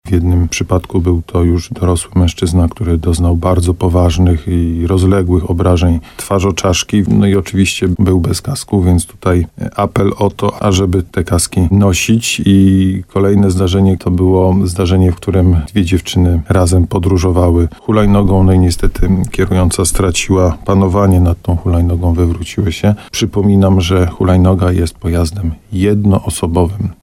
Gość programu Słowo za Słowo na antenie RDN Nowy Sącz zauważył, że na razie wiosną na drogach jest bezpiecznie.